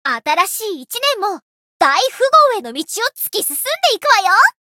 灵魂潮汐-叶月雪-春节（相伴语音）.ogg